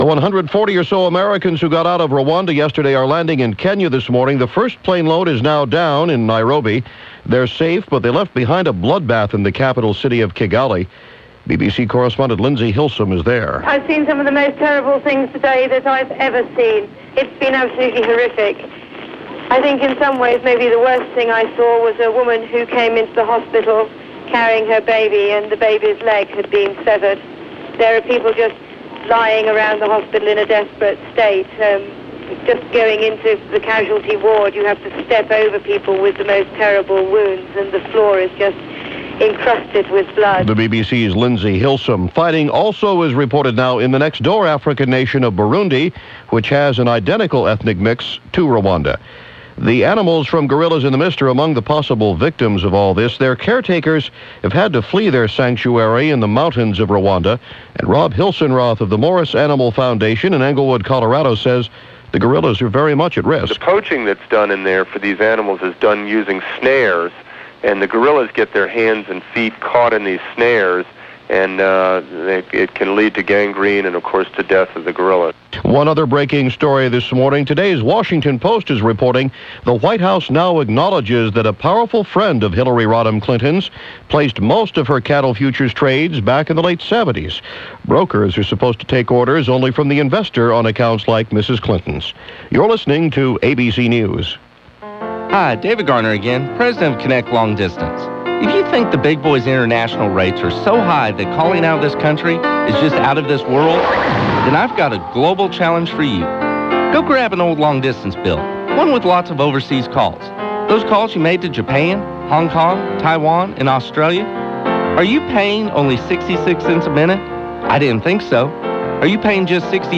ABC Hourly News